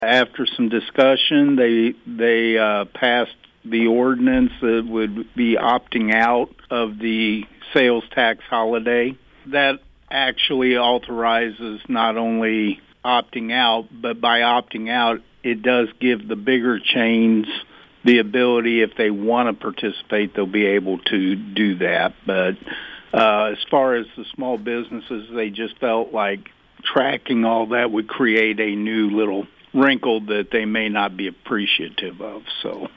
City Administrator Dale Klussman says the board passed an ordinance to opt-out of the Tax Holiday.